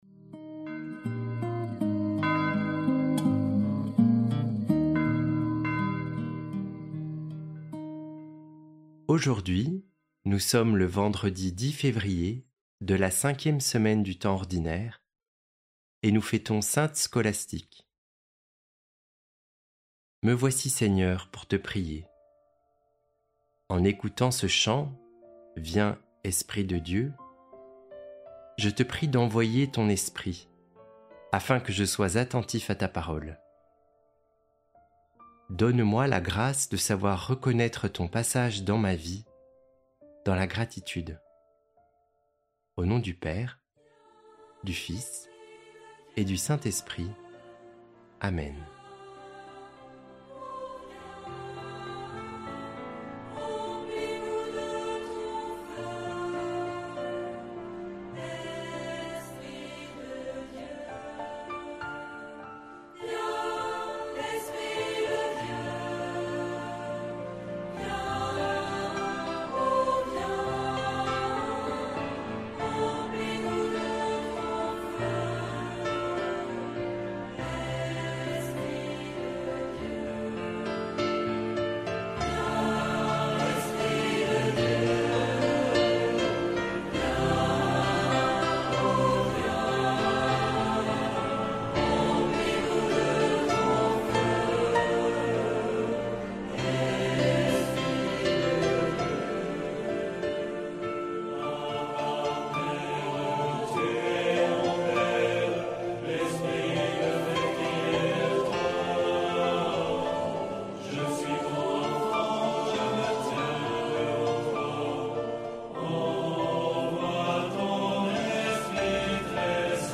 Musiques